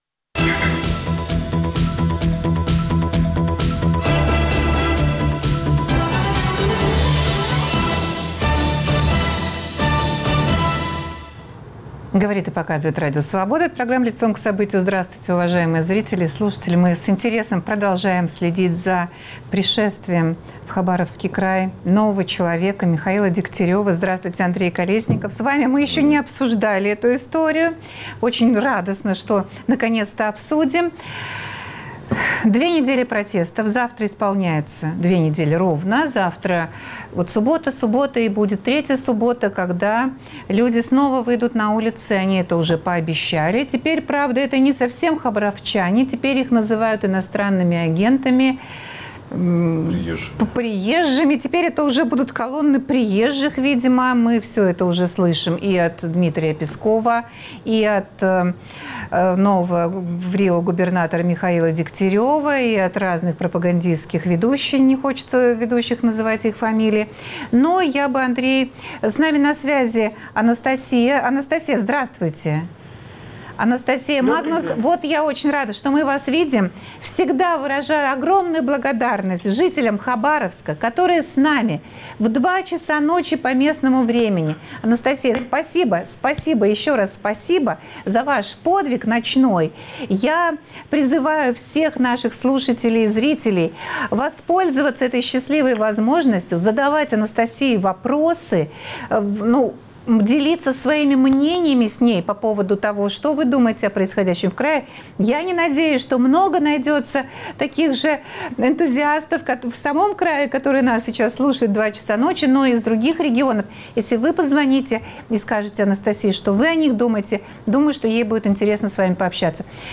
К чему приведет это противостояние? В эфире аналитик фонда Карнеги